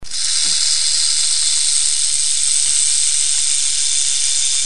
0:00 Group: Tiere ( 654 218 ) Rate this post Download Here!